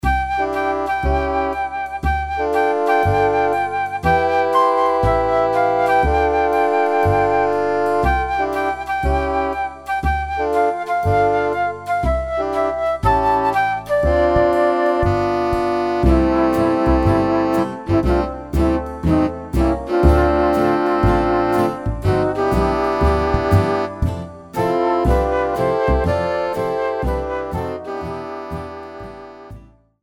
en estilo Swing
• Afinación original: Do